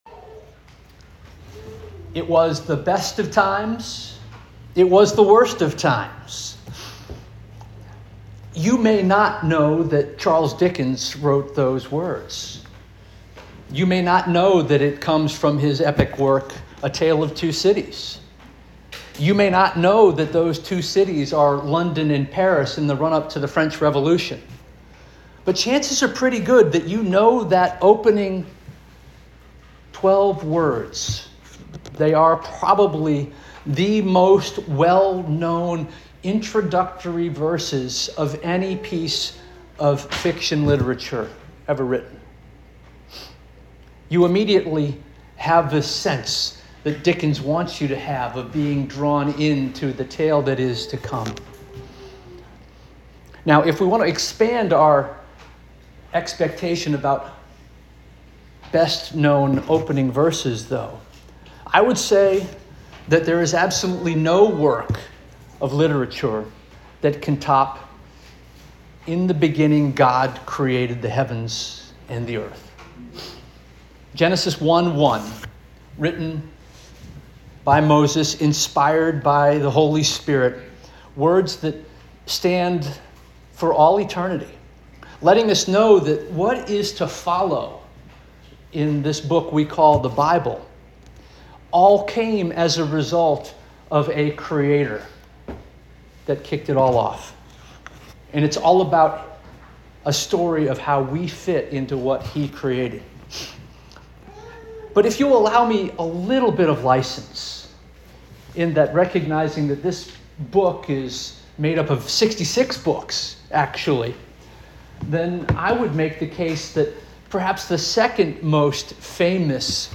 July 13 2025 Sermon